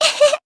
Dosarta-Vox_Happy1_jp.wav